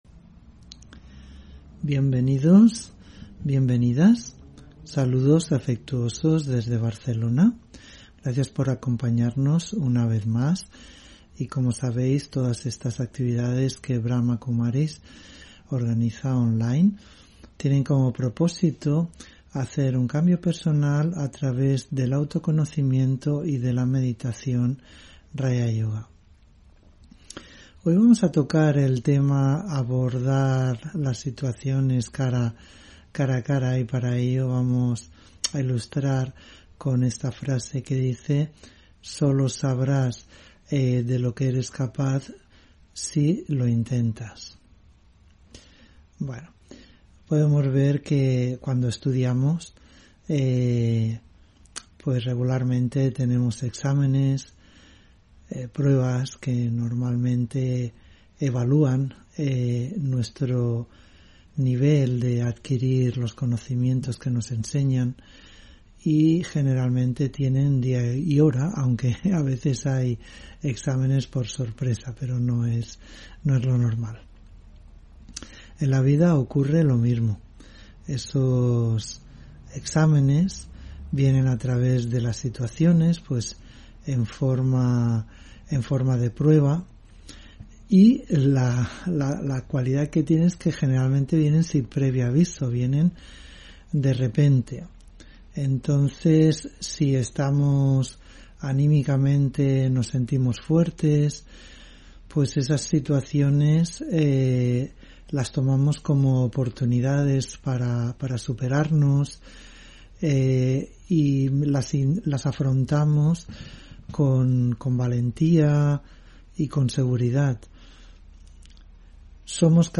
Meditación y conferencia: Abordar las situaciones cara a cara (11 Noviembre 2021)